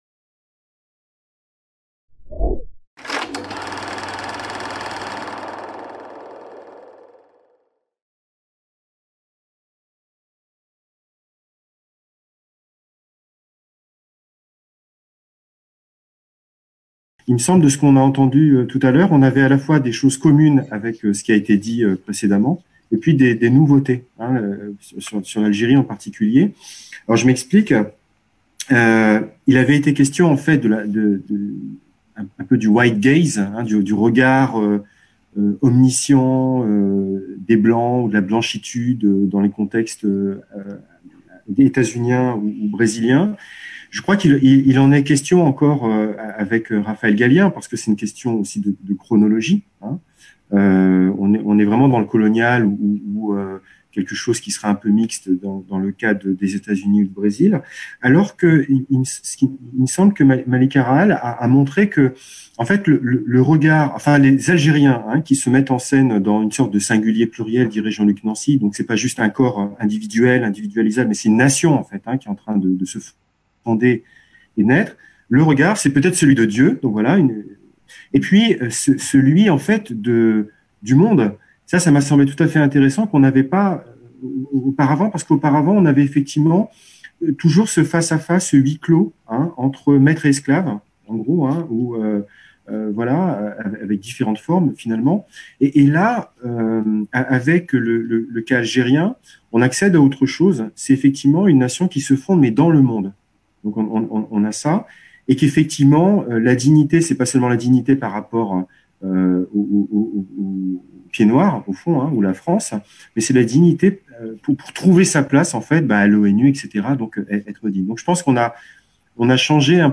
Corps et performances de dignité en contexte (post-)colonial. Amériques, Afrique, Océan indien - Discussion 2 | Canal U